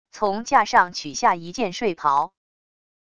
从架上取下一件睡袍wav音频